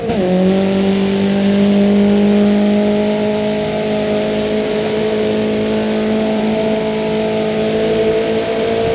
Index of /server/sound/vehicles/lwcars/renault_alpine
fourth_cruise.wav